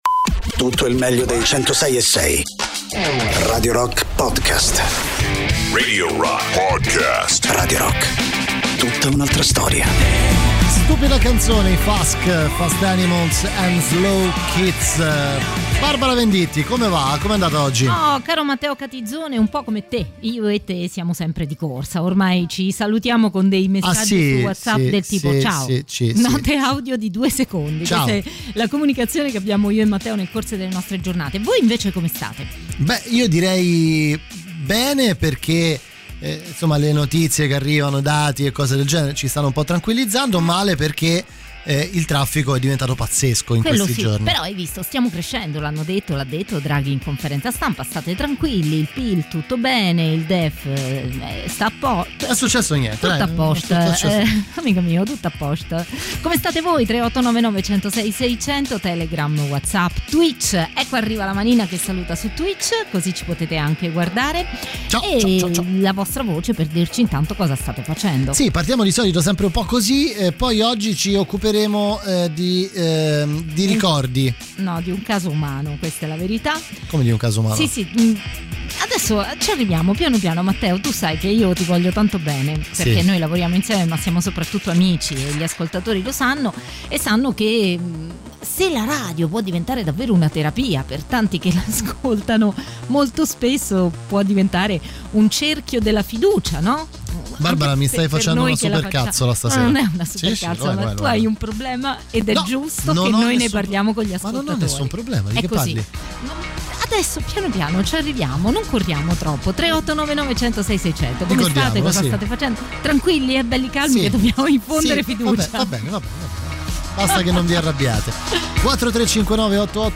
in diretta dal lunedì al venerdì, dalle 19 alle 21